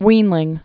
(wēnlĭng)